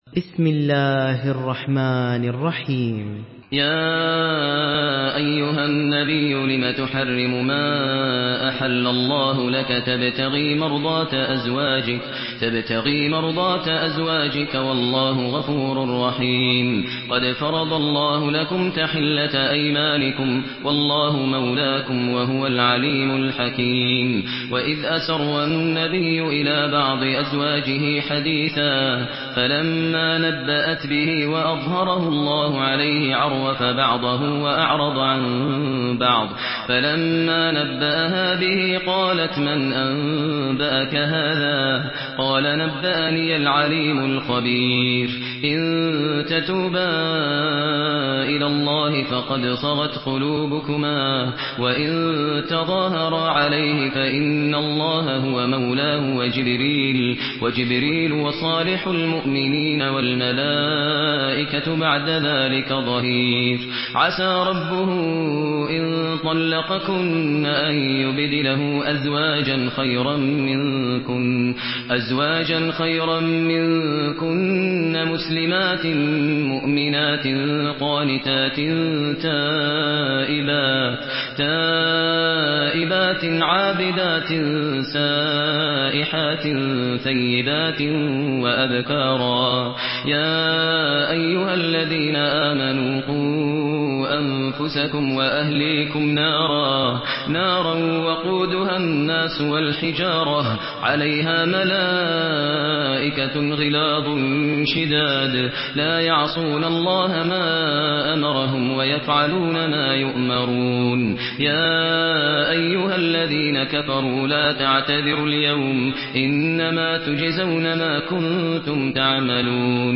Surah At-Tahrim MP3 in the Voice of Maher Al Muaiqly in Hafs Narration
Listen and download the full recitation in MP3 format via direct and fast links in multiple qualities to your mobile phone.